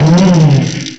cry_not_eelektrik.aif